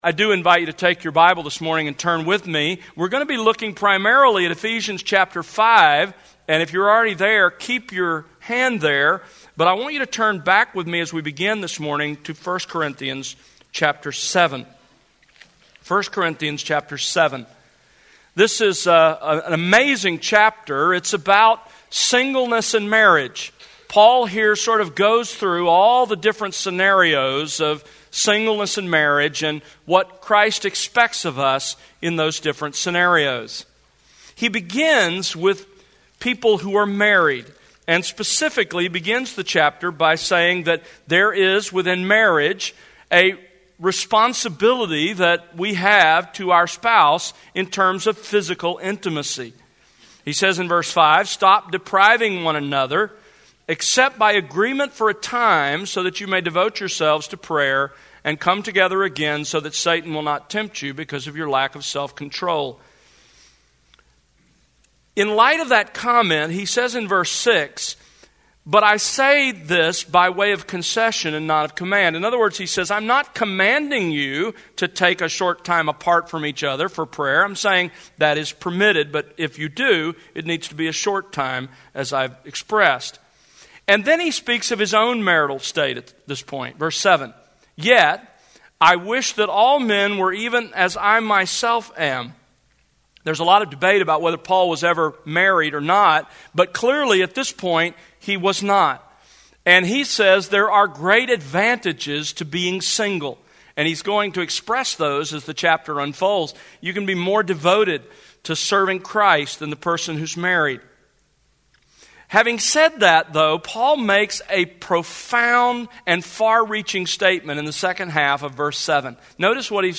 Sermons | The World Unleashed